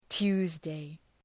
Προφορά
{‘tu:zdı}